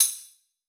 soft-slidertick.wav